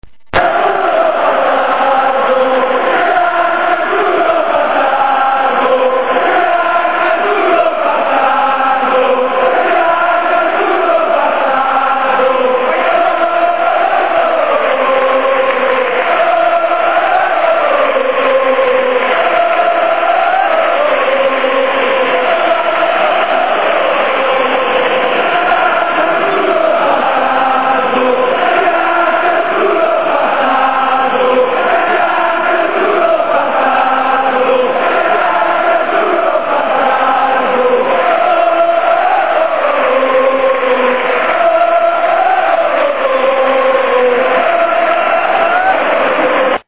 popopopo.mp3